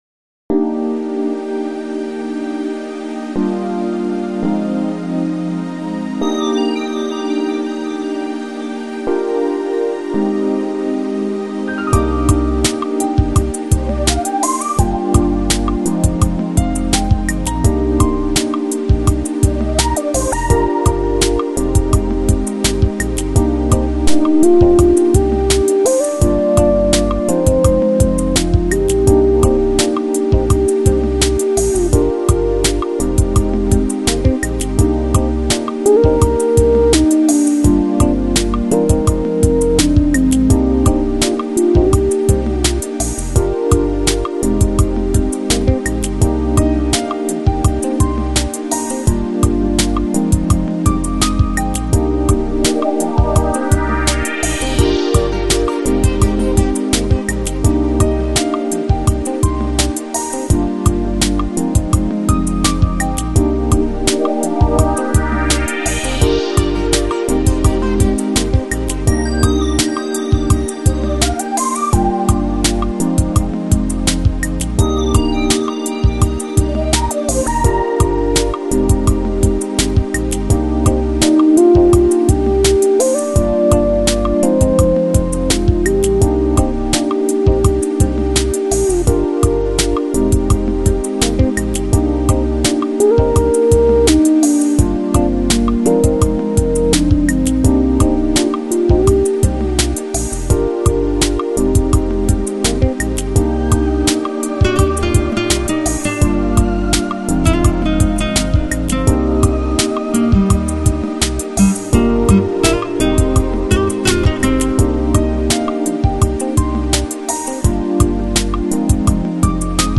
Lounge, Chill Out, Smooth Jazz, Easy Listening Год издания